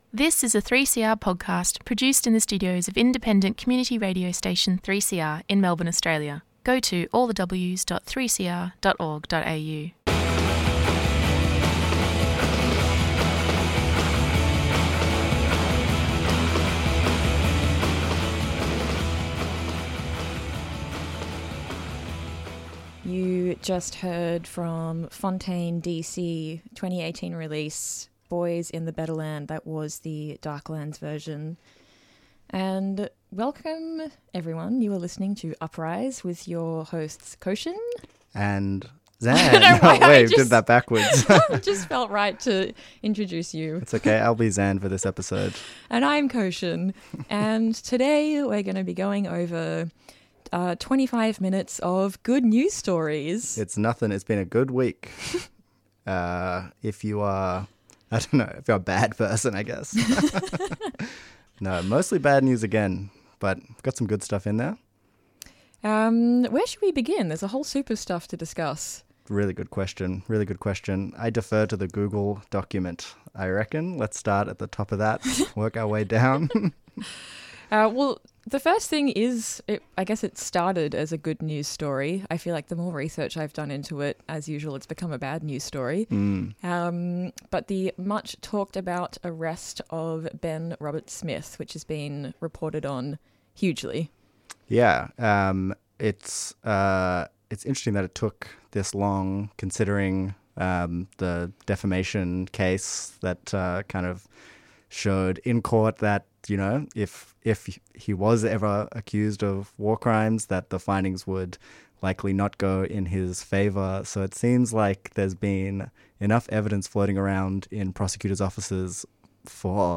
Tweet Uprise Radio Wednesday 5:30pm to 6:00pm A radical current affairs program providing a critical analysis of a topic in the news cycle that deserves closer inspection.